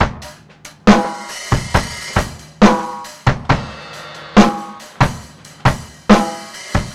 C BEAT 2  -R.wav